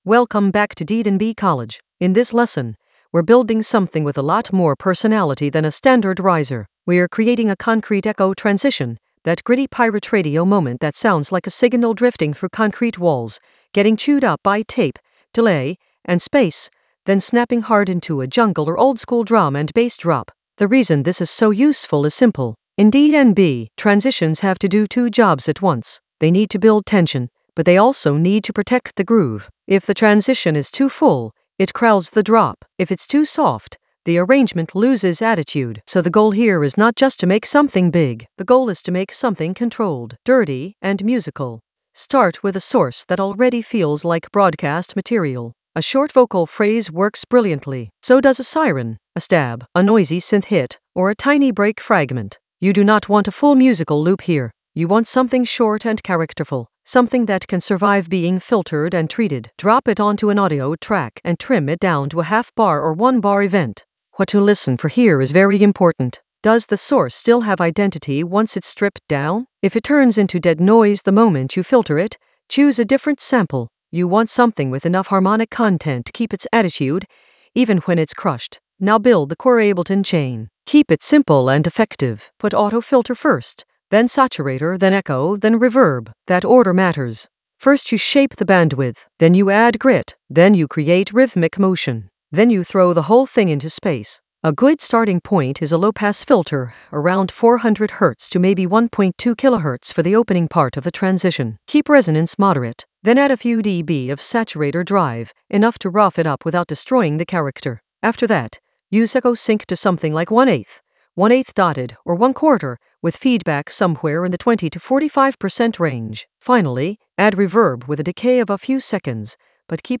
An AI-generated intermediate Ableton lesson focused on Concrete Echo a pirate-radio transition: design and arrange in Ableton Live 12 for jungle oldskool DnB vibes in the Automation area of drum and bass production.
Narrated lesson audio
The voice track includes the tutorial plus extra teacher commentary.